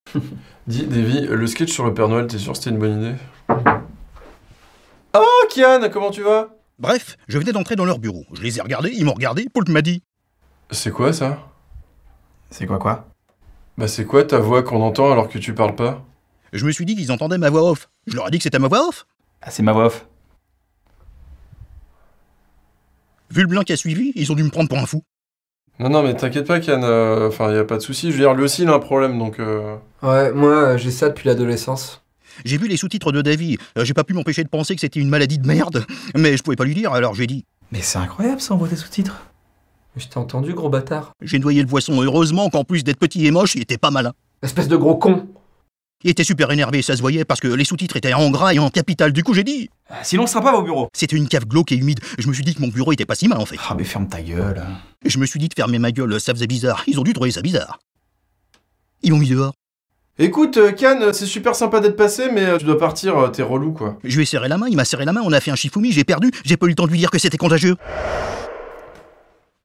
VOIX OFF – WEB-SERIE « Bref » (la voix off)